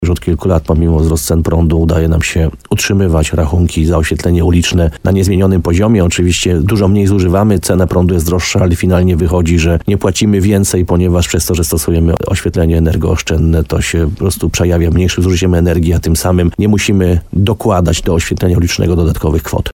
Jak mówi burmistrz Piotr Ryba, przyniesie to oszczędności.